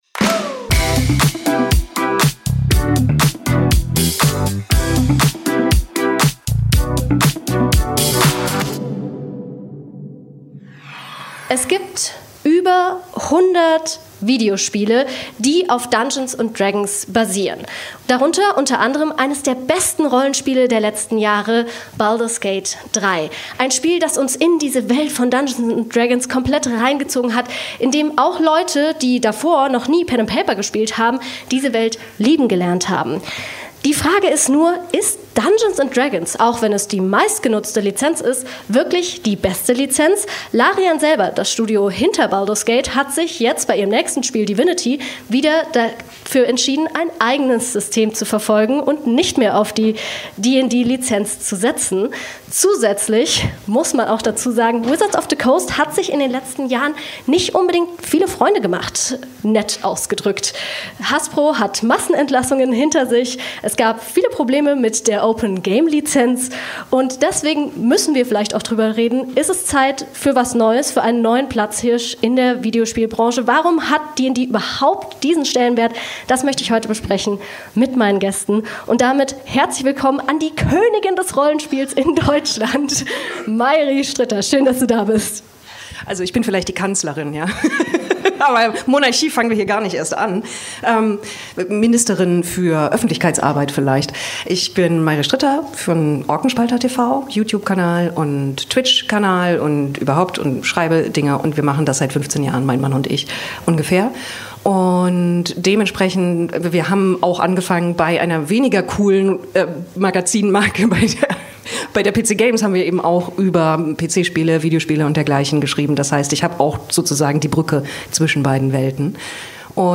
Wir haben die Bavariacon besucht, um live vor Ort eine Antwort zu finden!